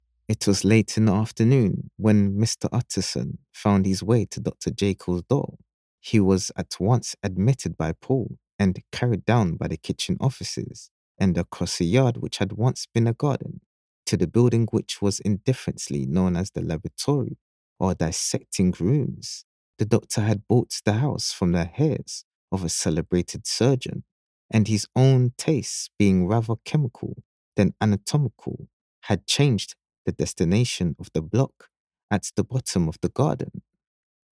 Audio Book Voice Over Narrators
English (Caribbean)
Adult (30-50) | Yng Adult (18-29)